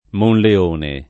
Monleone [ monle 1 ne ]